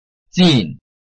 臺灣客語拼音學習網-客語聽讀拼-饒平腔-鼻尾韻
拼音查詢：【饒平腔】zin ~請點選不同聲調拼音聽聽看!(例字漢字部分屬參考性質)